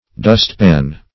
dustpan - definition of dustpan - synonyms, pronunciation, spelling from Free Dictionary
dustpan \dust"pan`\ (d[u^]st"p[a^]n`), n.